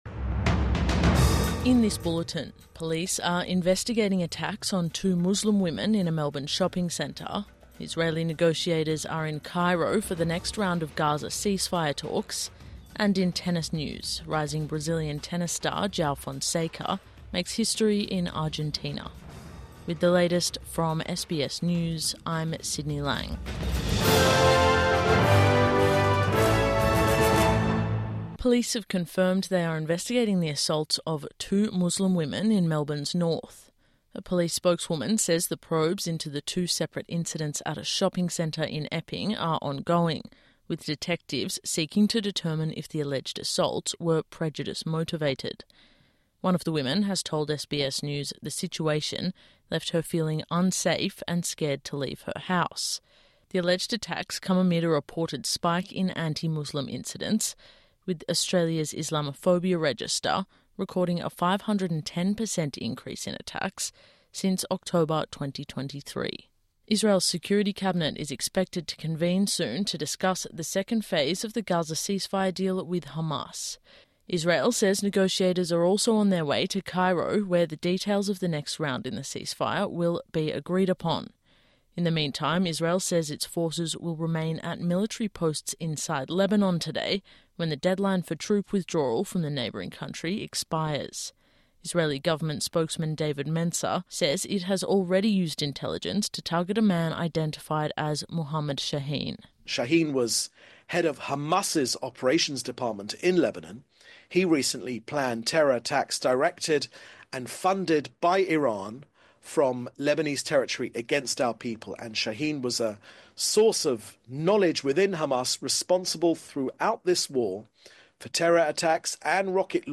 Police investigate attacks on Muslim women in Melbourne | Morning News Bulletin 18 February 2025